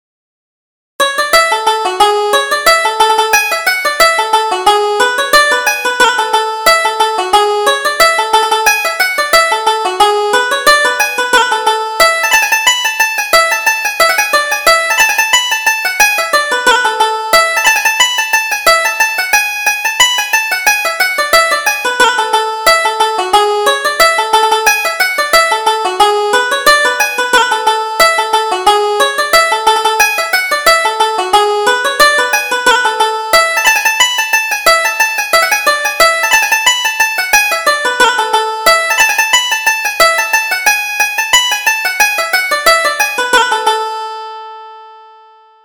Reel: The Maid in the Cherry Tree